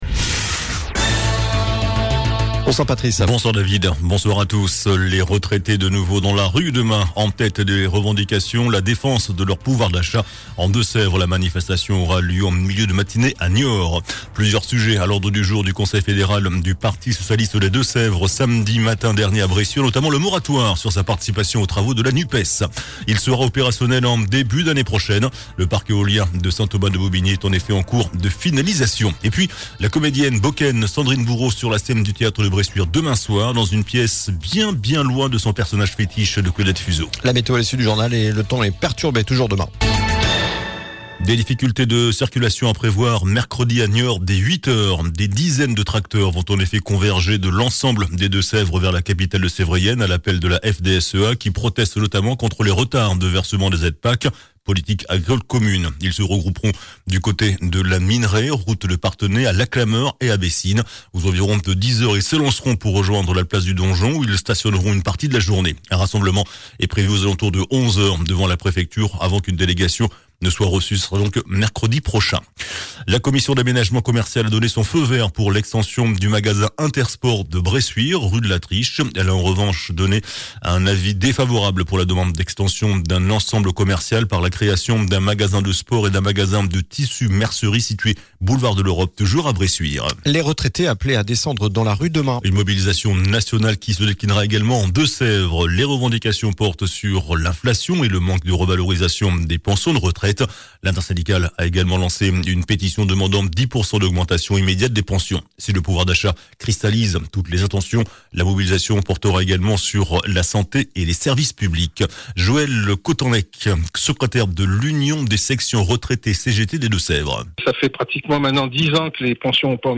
JOURNAL DU LUNDI 23 OCTOBRE ( SOIR )